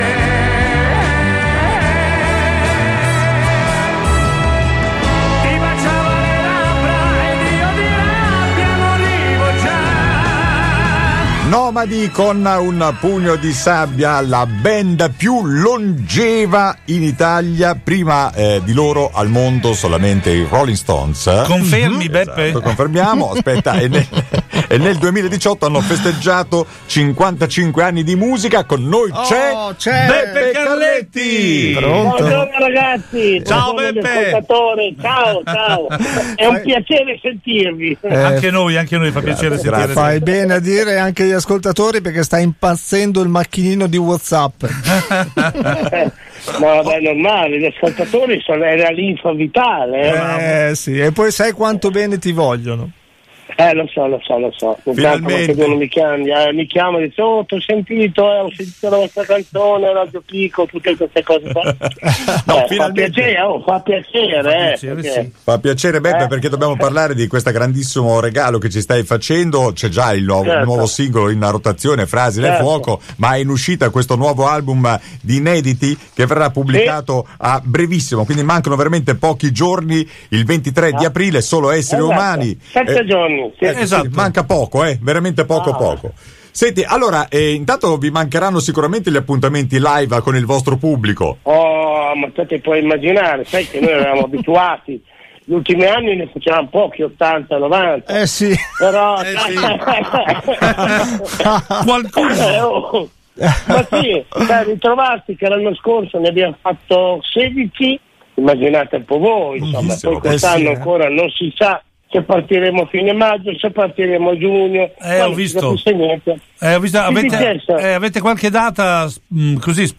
Radio Pico intervista Beppe Carletti - Radio Pico
Una divertente chiacchierata tra aneddoti, racconti, risate e novità: